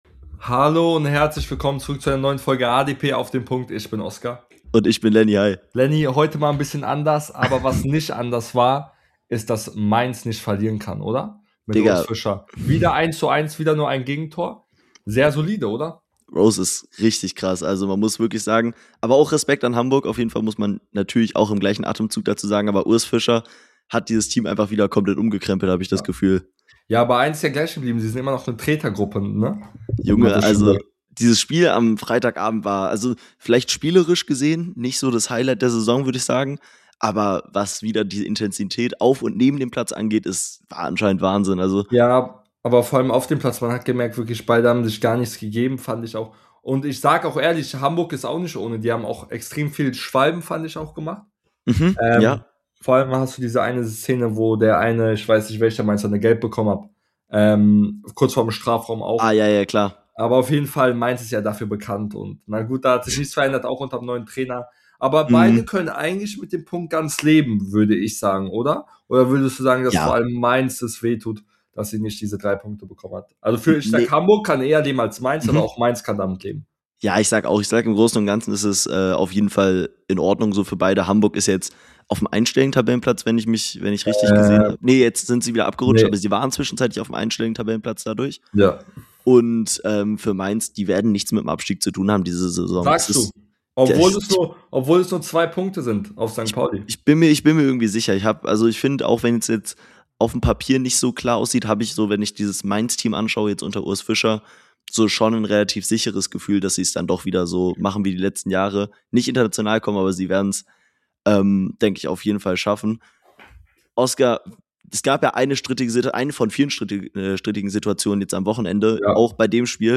In der heutigen Folge sprechen die beiden Hosts über Bremens Krise , blicken auf den Klassiker und machen ein formcheck der Aufstiegskandidaten der 2 Liga